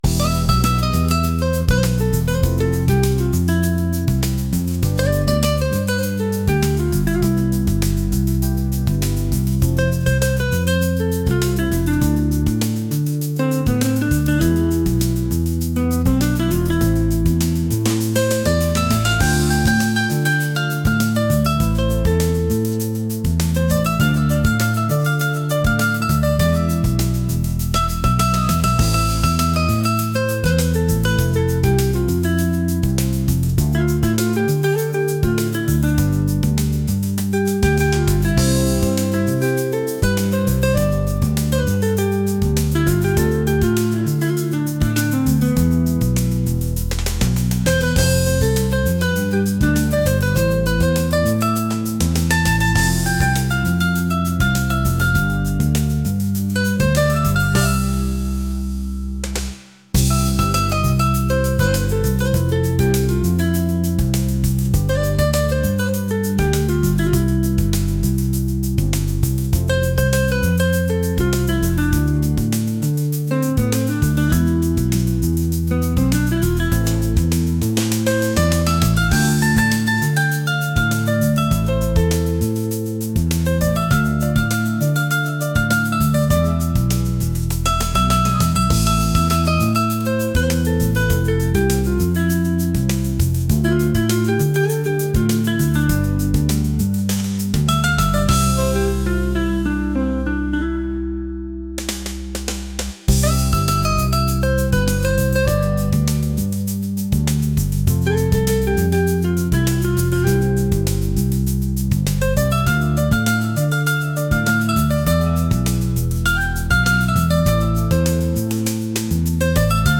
fusion | world